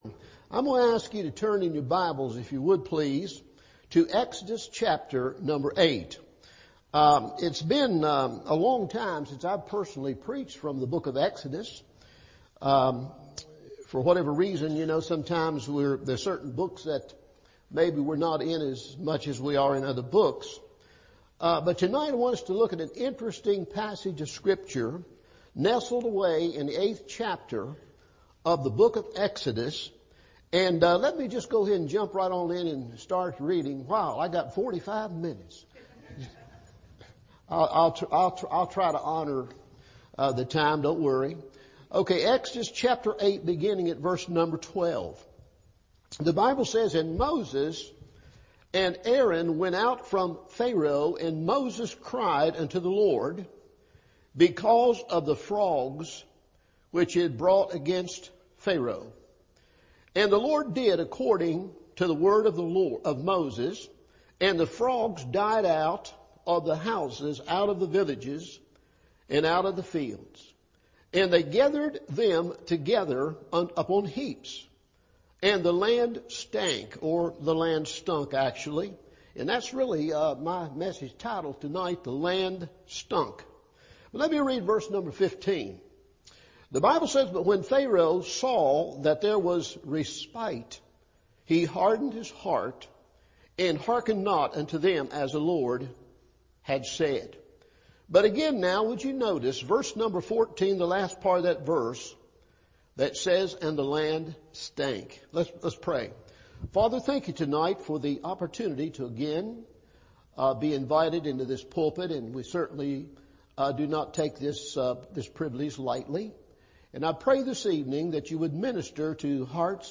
The Land Stunk – Evening Service